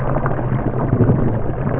water_59_02.wav